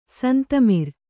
♪ santamir